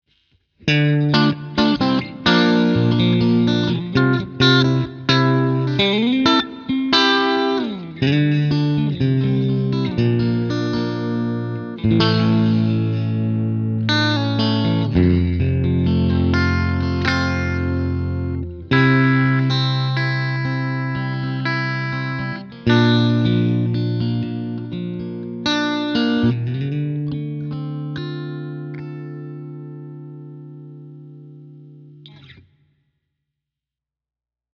All audio clips are recorded with a Marshall JCM900 head, set on an extremely clean tone. The cabinet used is a 2×12 openback with Celestion Creamback 75 speakers.
Clean sound, no effects added
Guitar: Fender
Mode: Twin
Gain: 4/10